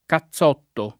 cazzotto [ ka ZZ0 tto ] s. m.